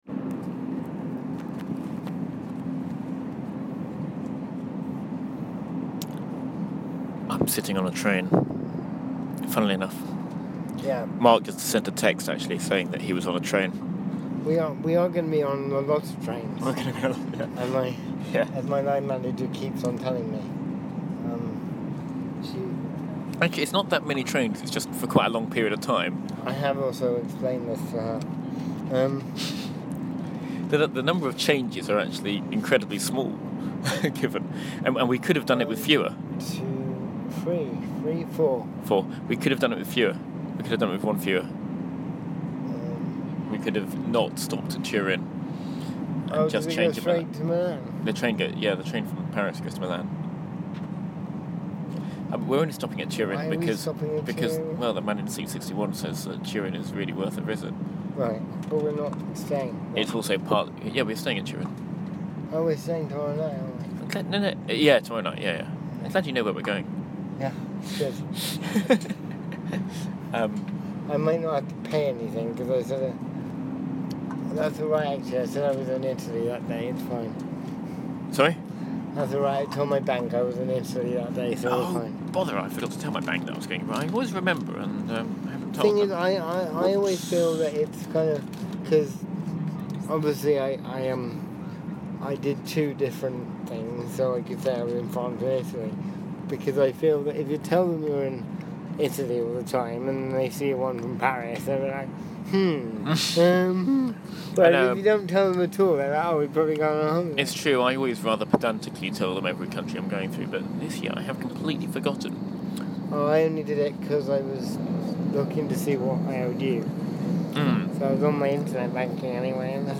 ...not in an Octupus's garden but in the Channel Tunnel.